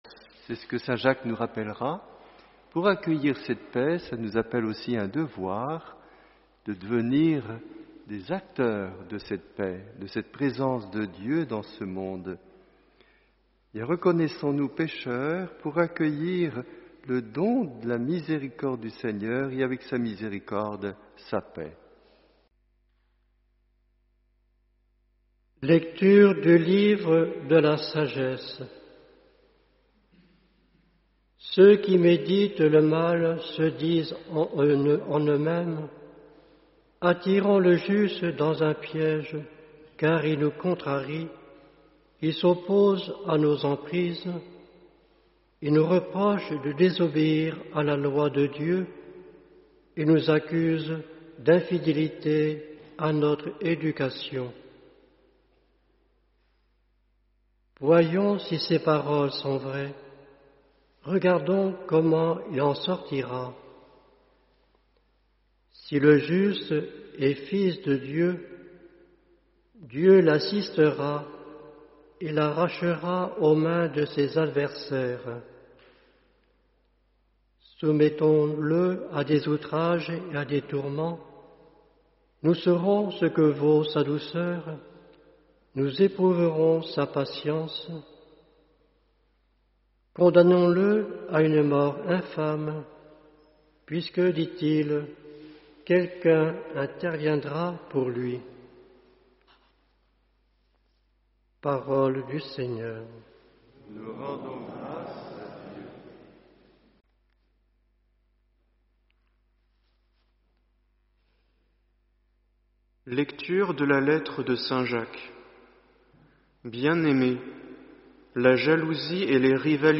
Homélie : Chers Frères et Sœurs, Mesurons-nous la grâce d’être chrétiens et présents chaque dimanche, le jour du Seigneur, pour […]